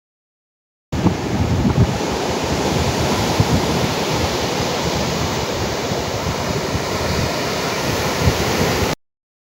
2、文本生成音频
选择“文本生成音效”，填写提示词（比如waves，海浪），设置时长，点击提交，生成结果位于右侧
声音效果